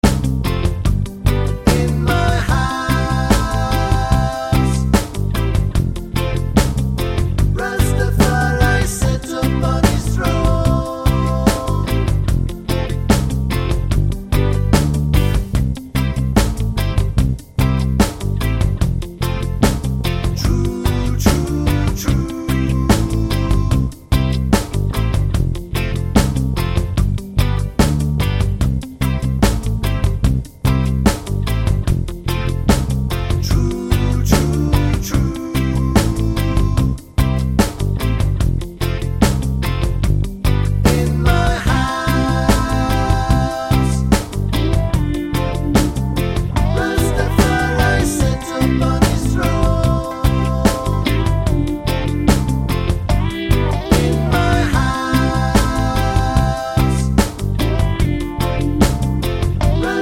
no Backing Vocals Reggae 4:40 Buy £1.50